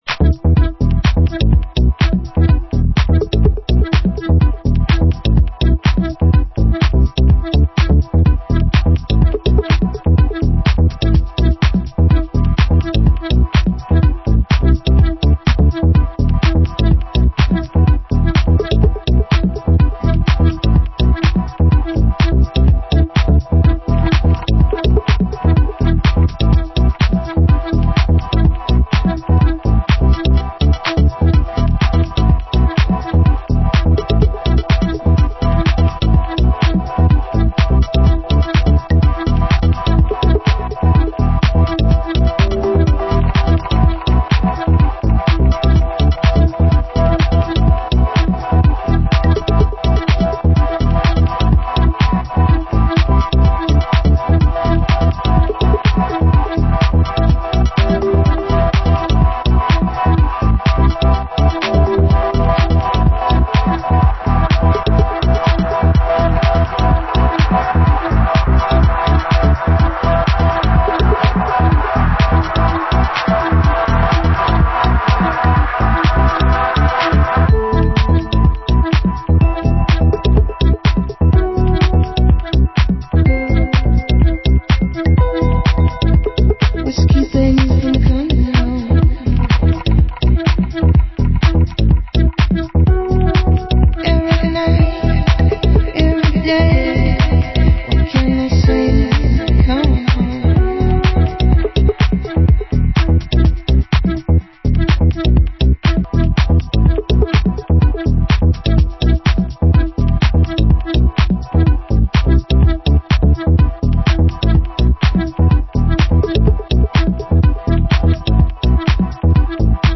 Format: Vinyl 12 Inch
Genre: Deep House